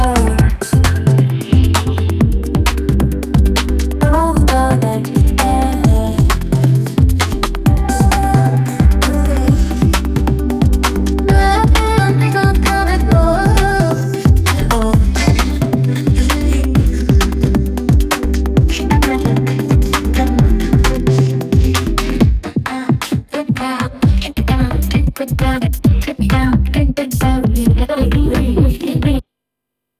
audio-to-audio k-pop music-generation
MusicGen trained on NewJeans with vocals